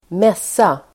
Uttal: [²m'es:a]